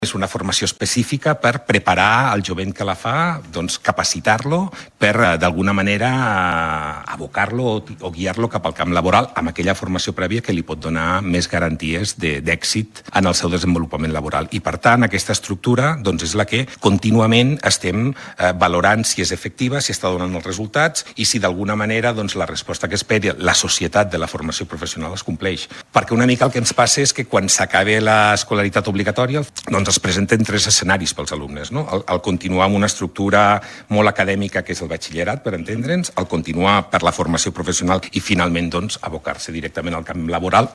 En el decurs de l’entrevista, Bardina ha destacat també el bon moment de la Formació Professional, que ja concentra el 30% de l’alumnat postobligatori.